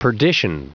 Prononciation du mot perdition en anglais (fichier audio)
Prononciation du mot : perdition